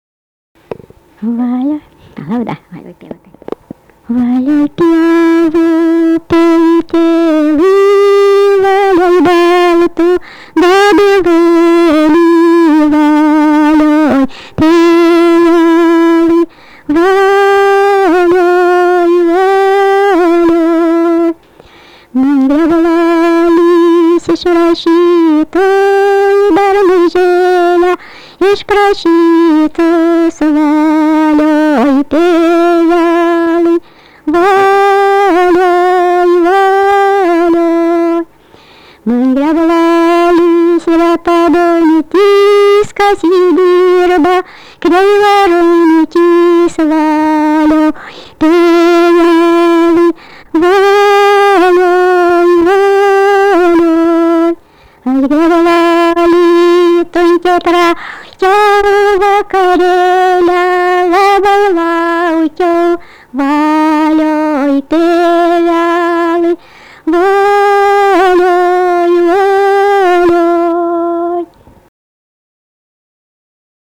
daina, kalendorinių apeigų ir darbo
Zastaučiai
vokalinis